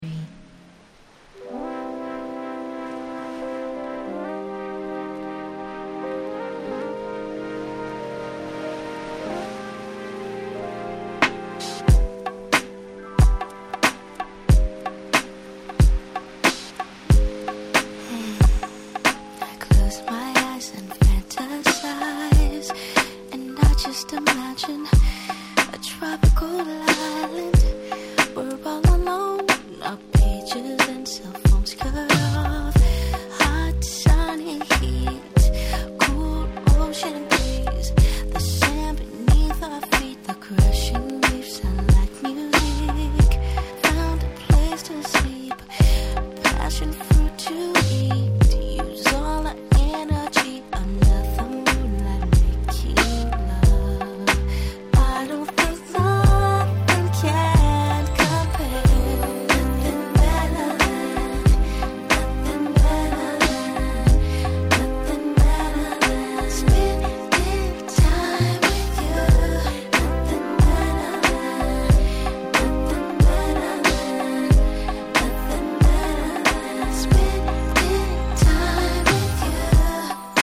話はそれましたが、本作も所々Neo Soul風味を感じさせる良曲がちらほら。